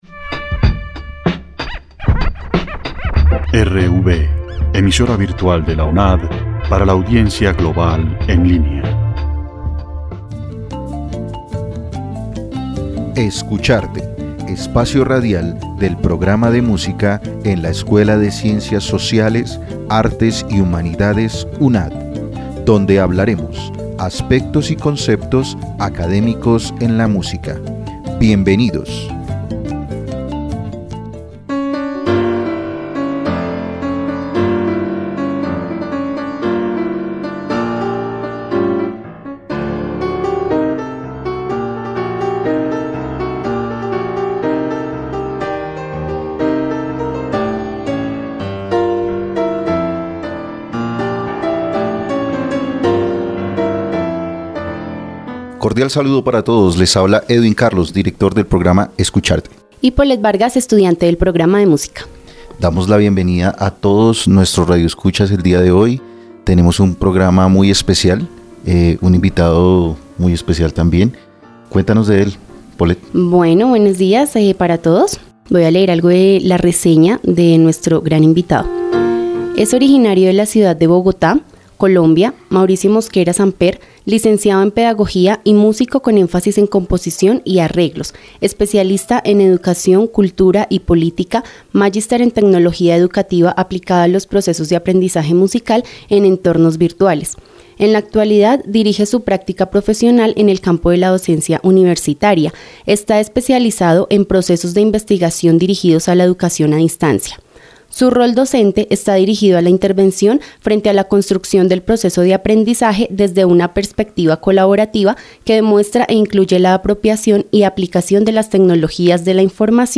Escucharte # 2 -Entrevista